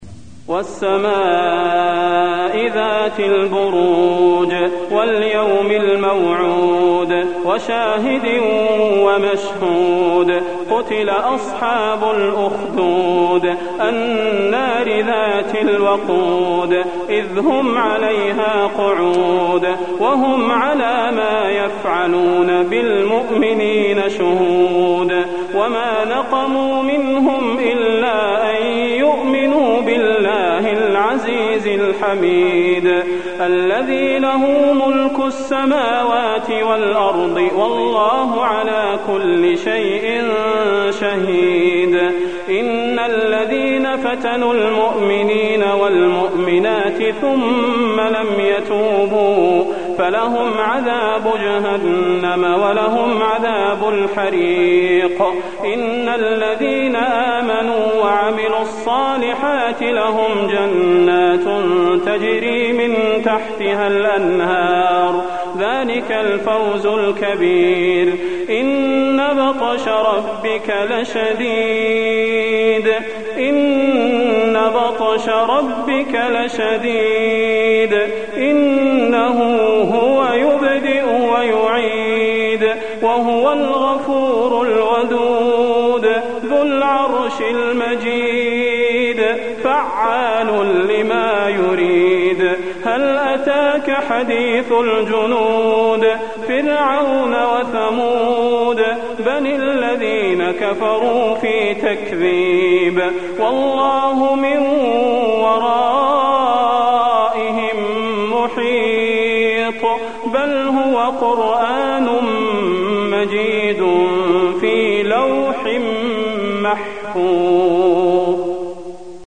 المكان: المسجد النبوي البروج